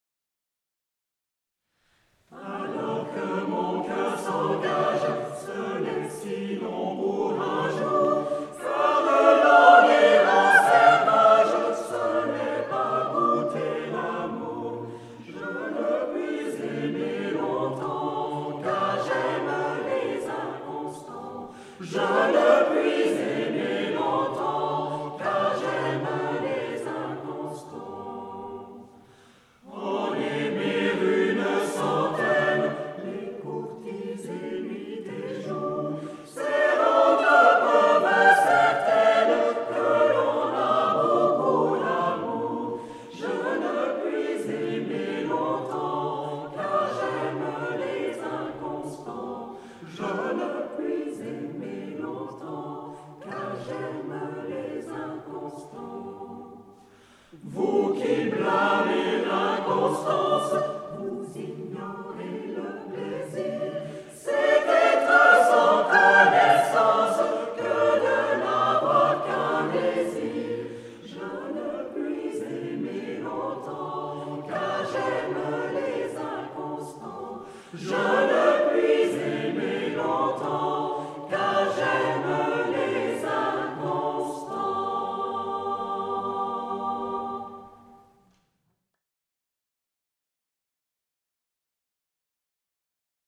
Polyphonie profane et sacrée d'Europe et des Amériques du XIVe au XXIe sièclephoto 2011
- Le dimanche 6 novembre 2011 à 20h00 à l'église catholique de Versoix, GE, Suisse.
Quelques extraits de Versoix :